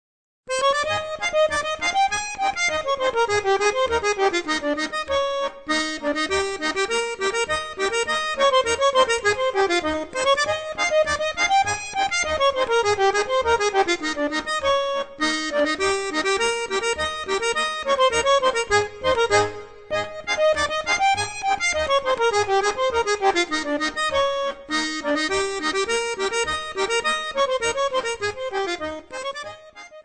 Stücke der originalen Volksmusik berücksichtigt.